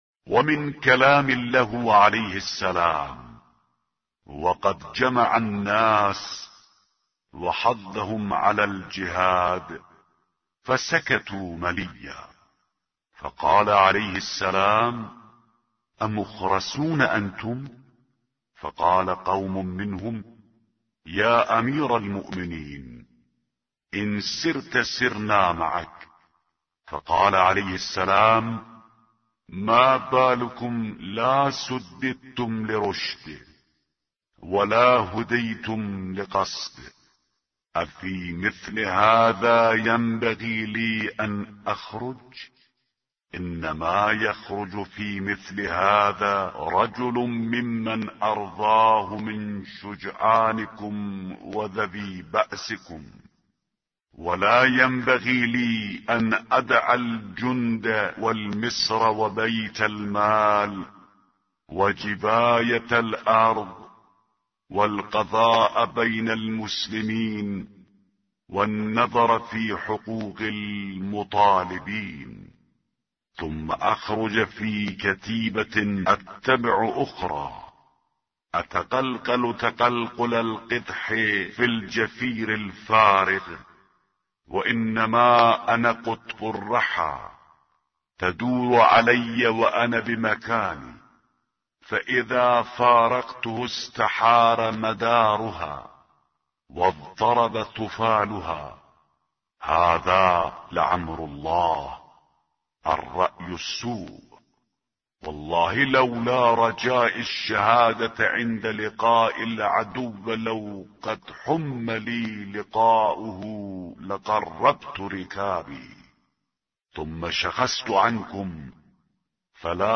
به گزارش وب گردی خبرگزاری صداوسیما؛ در این مطلب وب گردی قصد داریم، خطبه شماره ۱۱۹ از کتاب ارزشمند نهج البلاغه با ترجمه محمد دشتی را مرور نماییم، ضمنا صوت خوانش خطبه و ترجمه آن ضمیمه شده است.